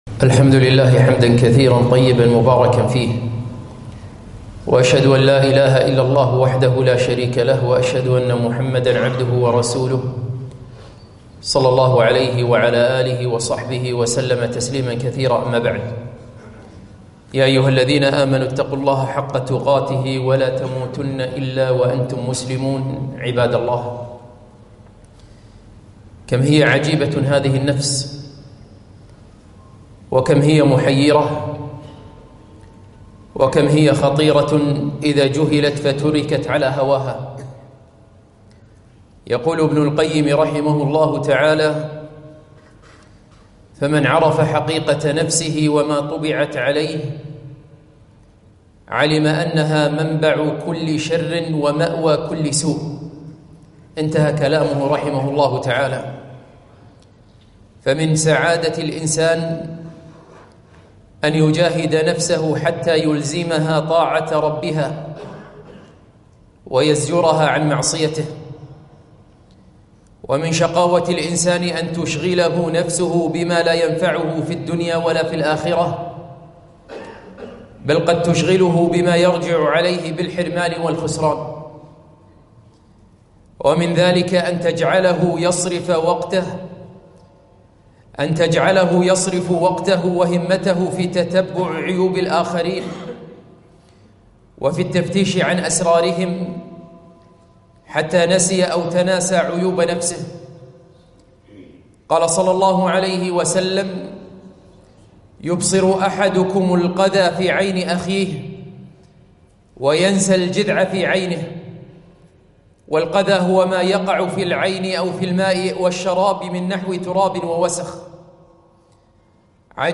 خطبة - دقق في عيوبك لا في عيوب الآخرين